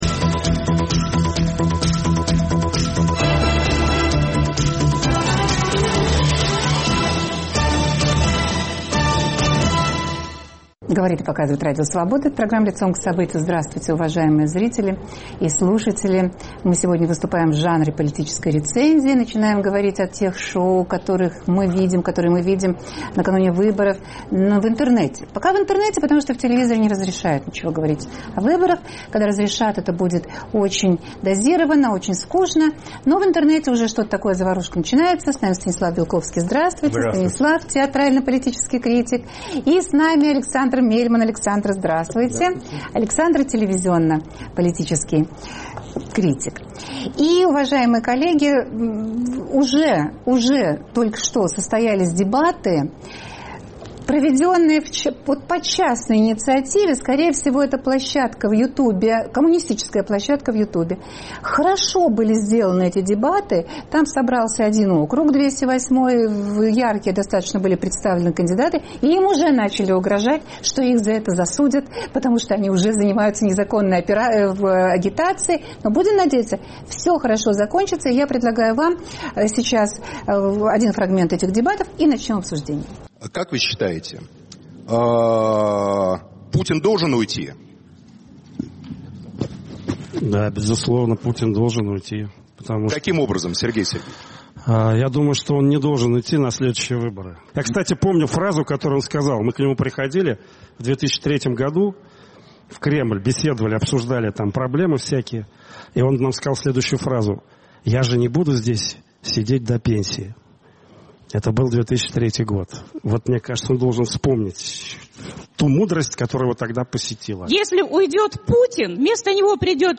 В студии